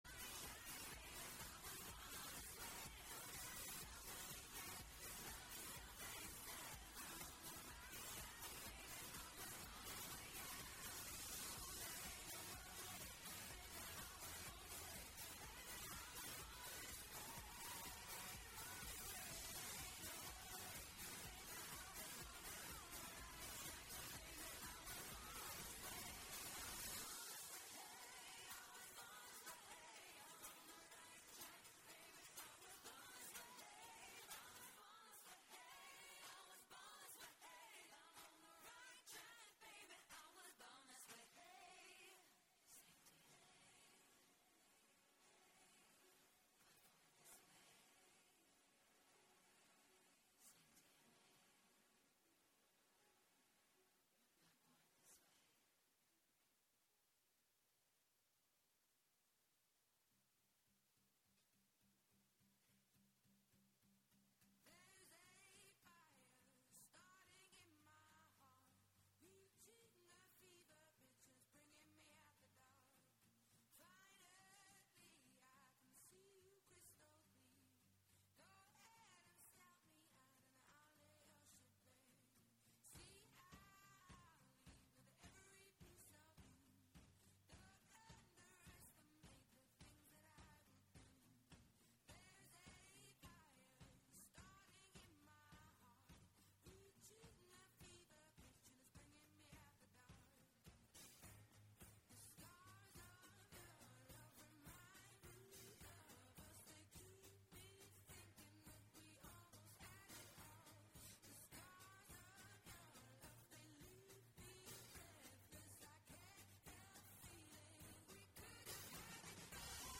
The Yarn Storytelling, in partnership with JCA, held its inaugural Maternal Health Storytelling show, which took place atThe Arkansas Repertory Theatre in Little Rock, Arkansas, on October 2nd, 2025.
maternal-health-storytelling-live-show-october-2-2025.mp3